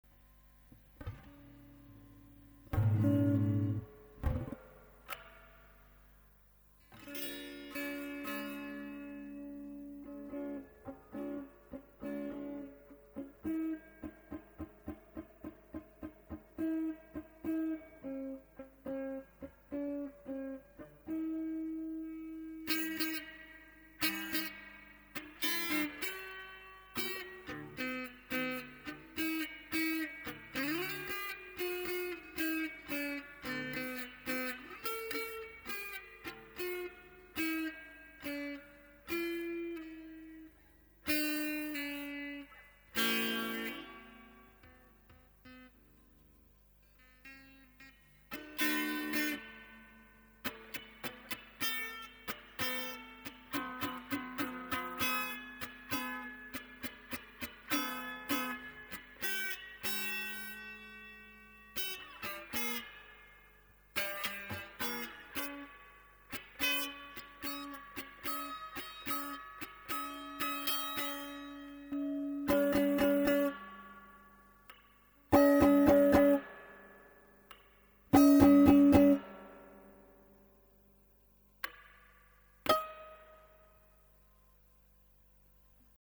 Spring効果だったんか、オペアンプ から スプリング通すと再生全く